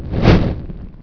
throw.wav